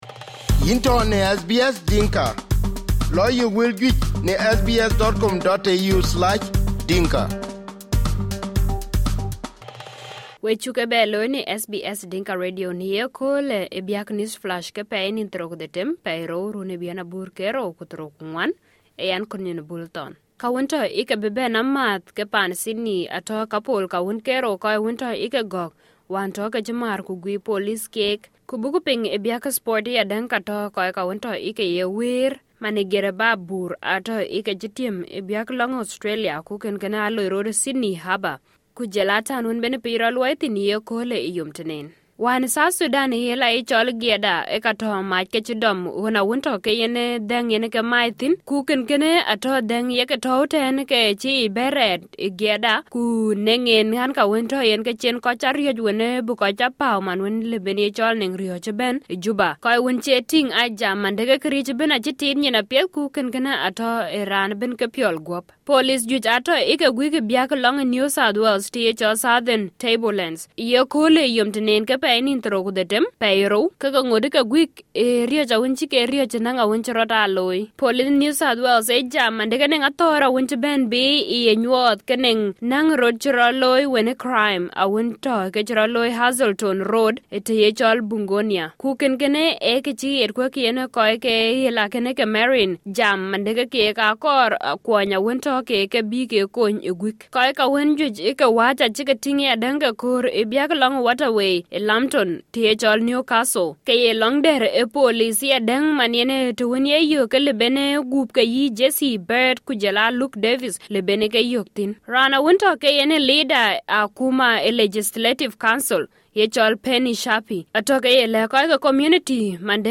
SBS Dinka News Flash 26/02/2024